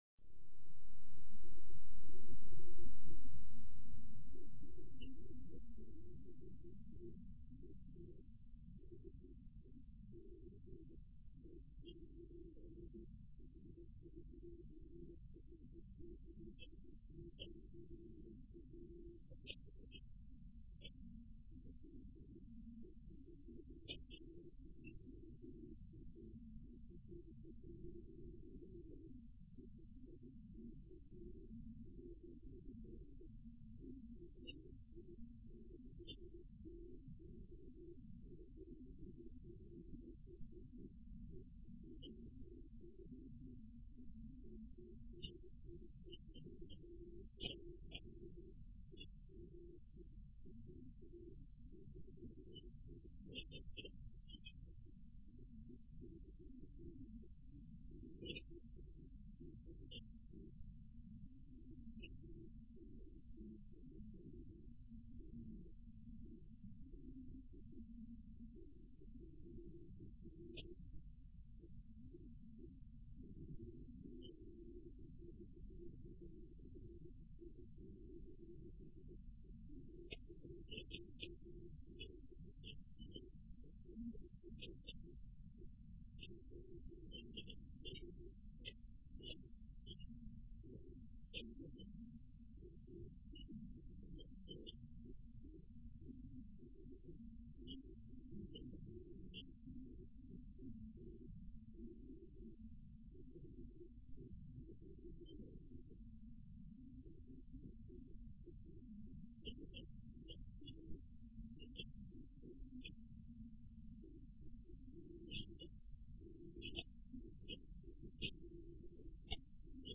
演奏版、